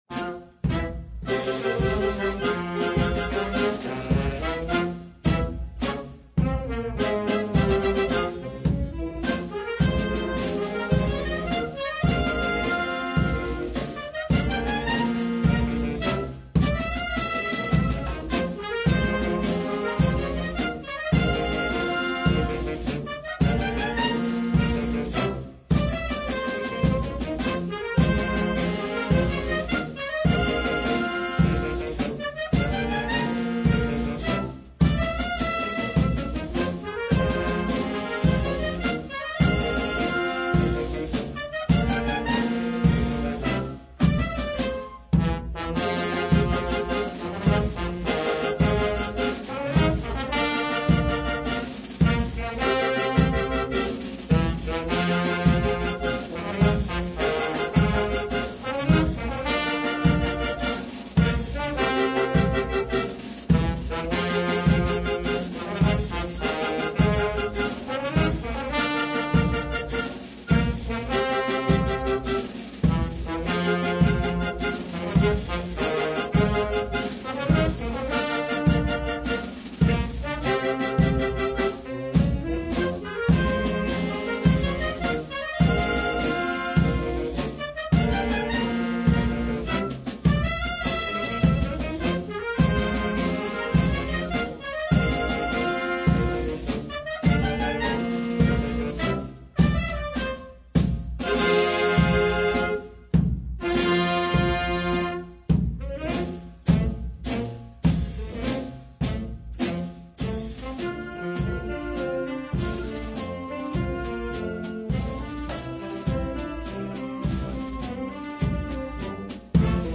A CARGO DO BPEB
SemInf-2025-DobradoComprimido-LegiaodaInfantaria.mp3